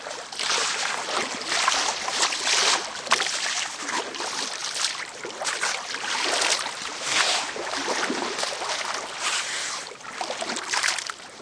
water_swimming_splashing_breath.ogg